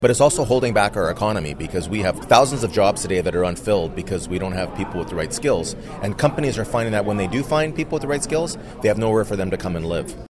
Following the session, Mayor Panciuk told Quinte News  the landlords’ main issue is with the provincial government.